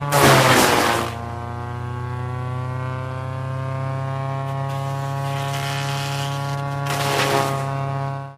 Mulcher Rips Wood 4x